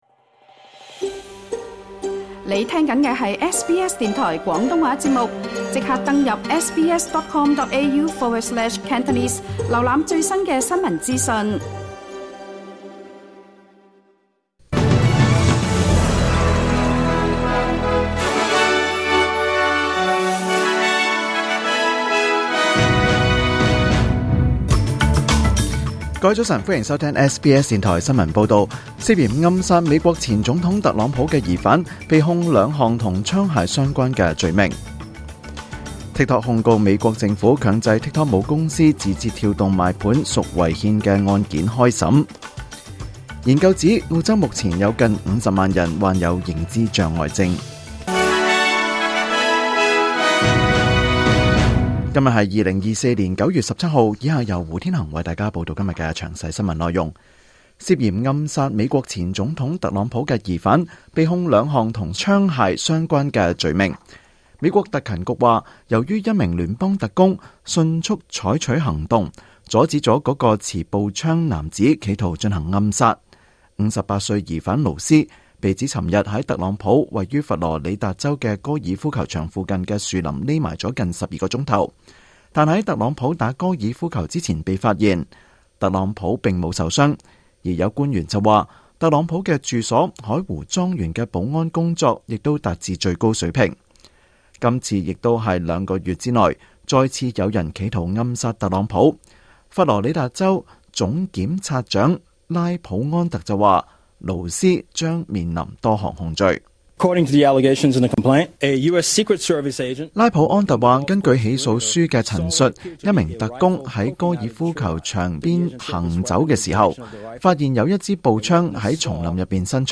2024年9月16日SBS廣東話節目詳盡早晨新聞報道。